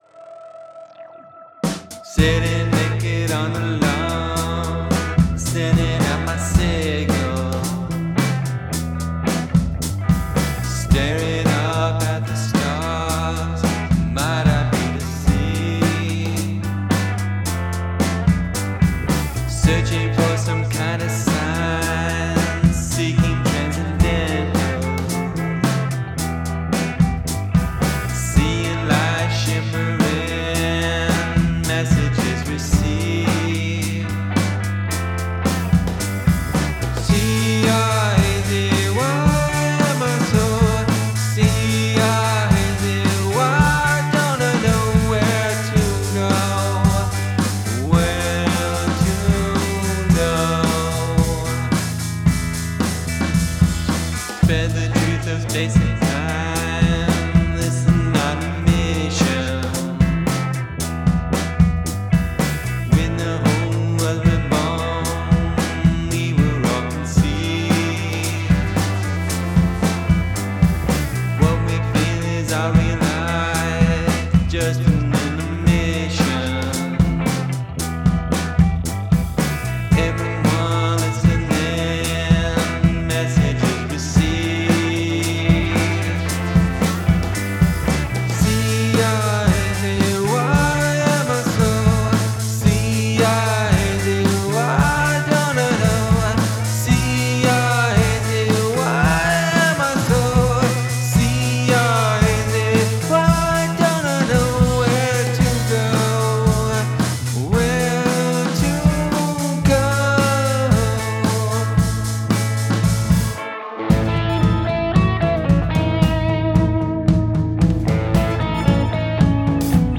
Use of spelling in a song.
Cool panning stuff is happening.